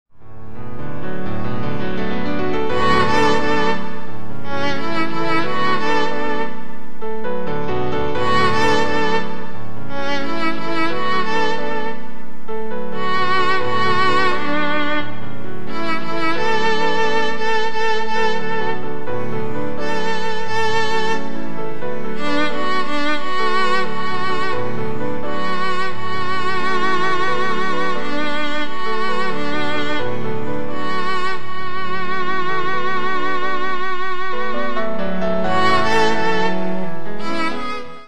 合唱 パート別・音取りＣＤ